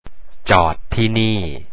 젓 티니 - 여기 세워 주세요 จอดที่นี่